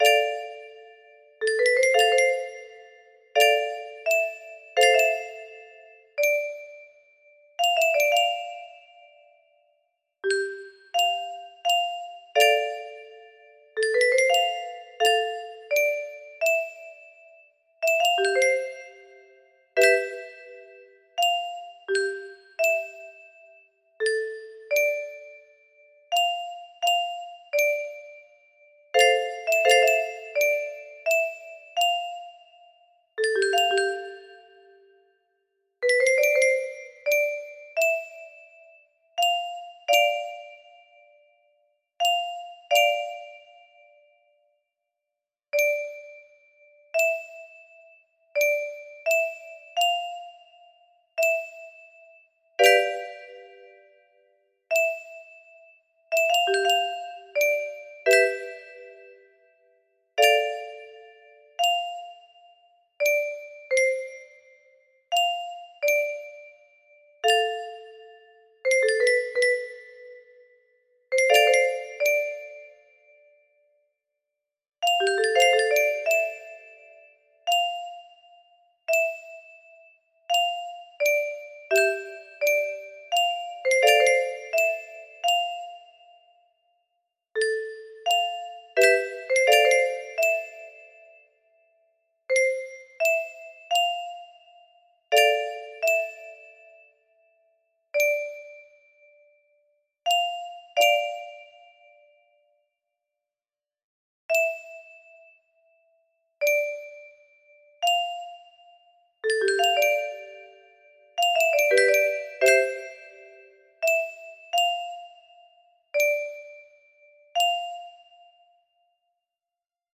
Major Scale F# 60bars 85bpm arpeggio musicbox